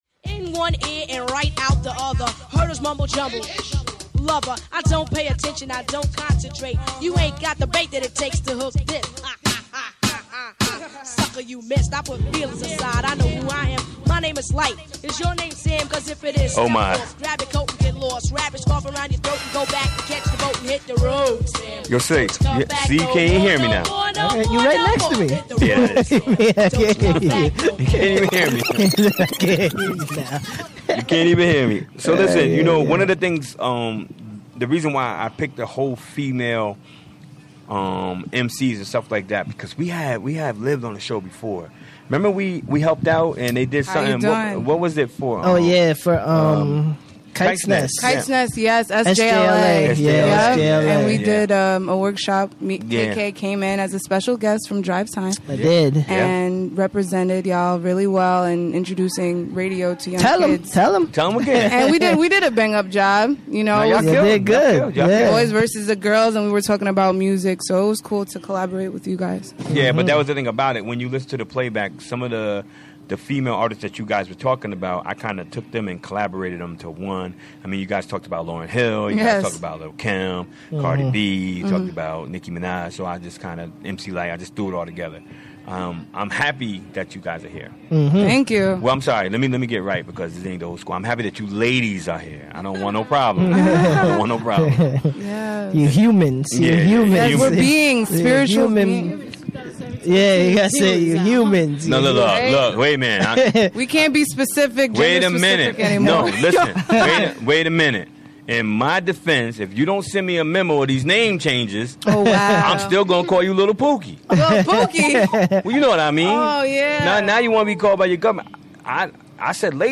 Live from Columbia Memorial Hospital: Jan 24, 2018: 4pm - 6pm